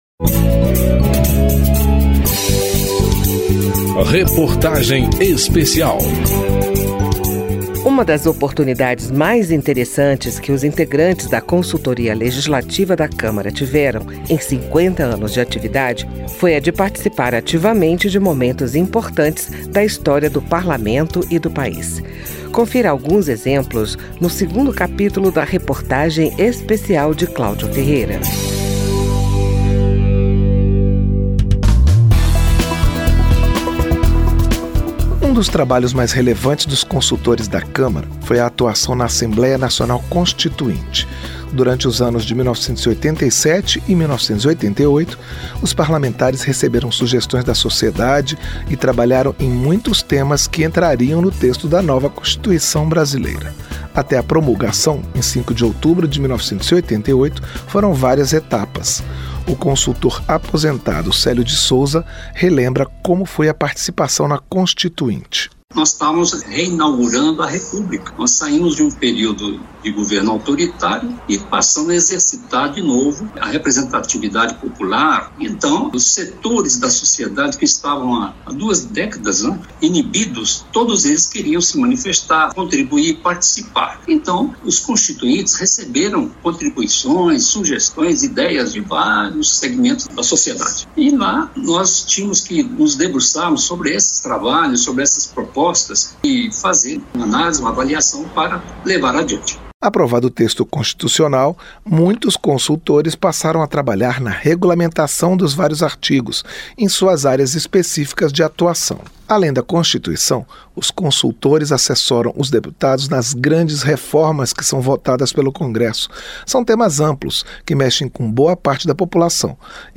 Reportagem Especial
reportagem-especial-consultoria-legislativa-02.mp3